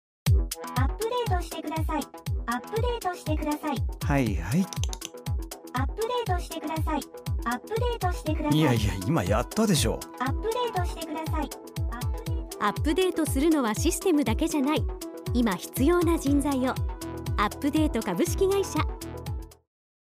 弊社のラジオCMがRADIO BERRY（FMとちぎ）で放映中です！